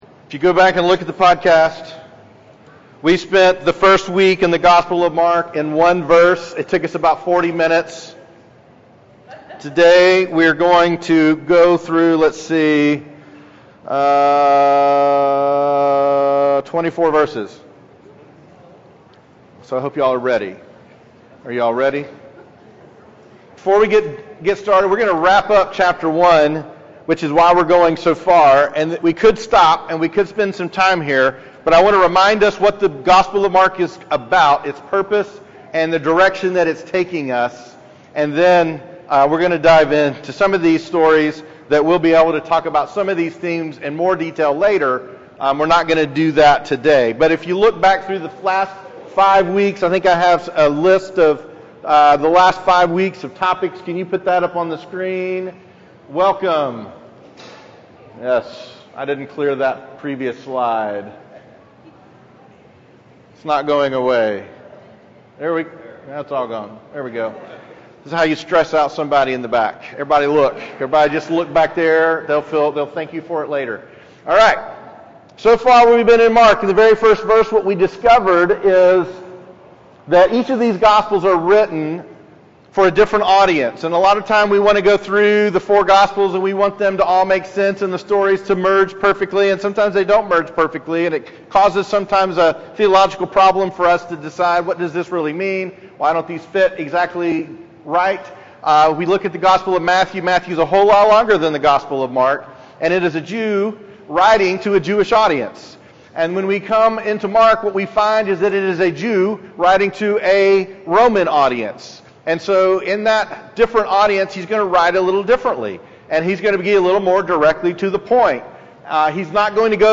Sermons - Journey Church